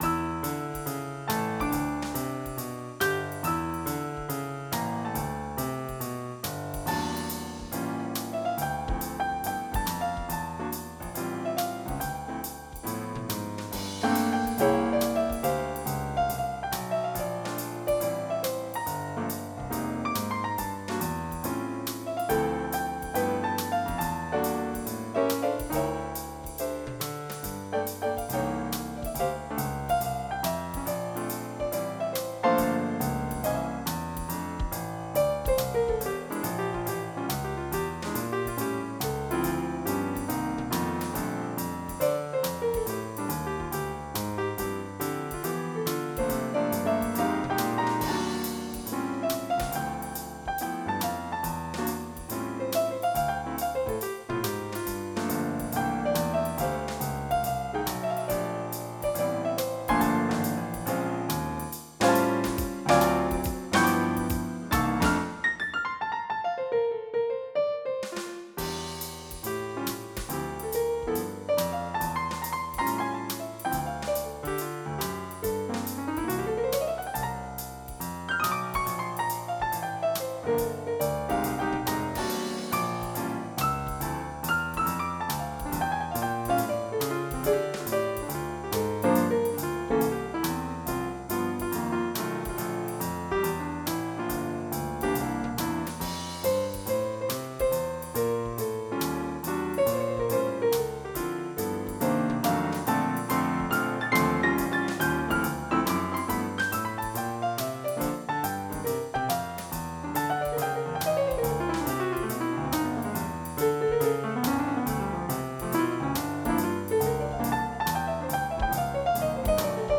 MIDI Music File
JAZZ23.mp3